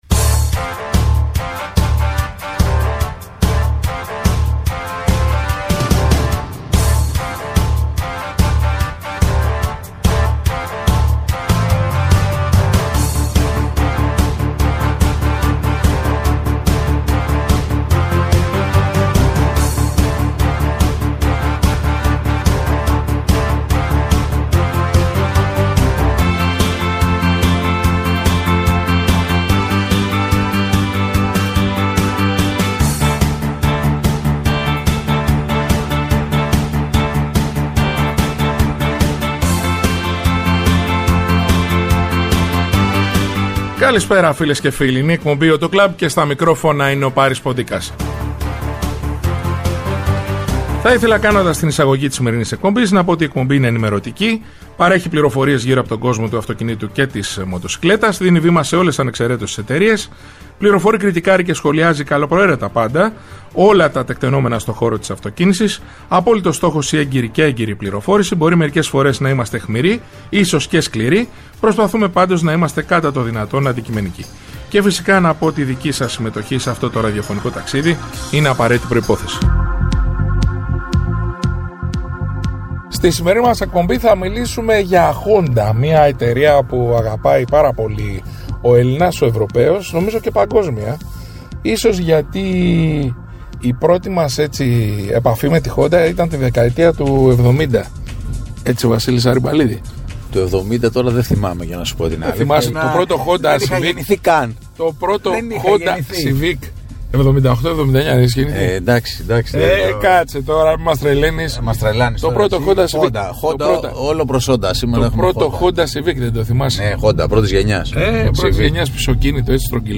Η εκπομπή «AUTO CLUB» είναι ενημερωτική, παρέχει πληροφορίες γύρω από τον κόσμο του αυτοκινήτου και της μοτοσικλέτας, δίνει βήμα σε όλες ανεξαιρέτως τις εταιρείες, φιλοξενεί στο στούντιο ή τηλεφωνικά στελέχη της αγοράς, δημοσιογράφους αλλά και ανθρώπους του χώρου.